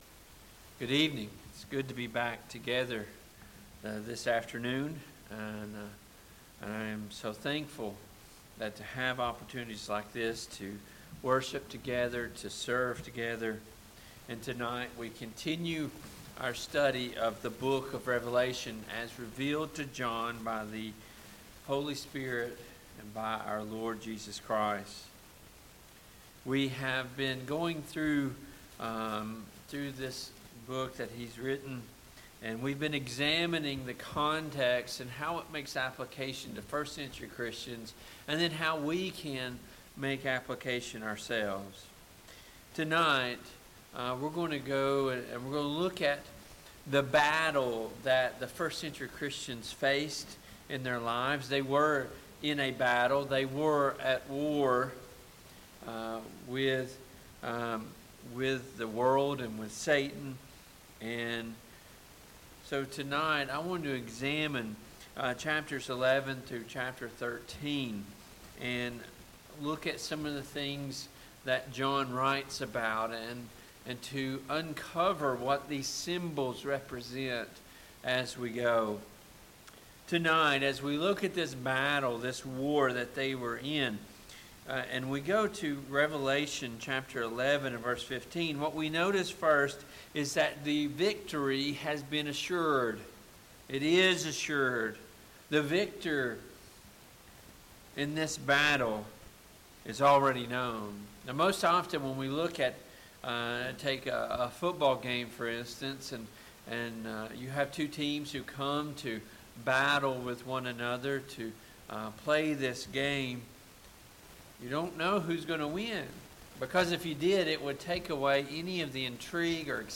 Passage: Revelation 11:15-13:18 Service Type: PM Worship Bible Text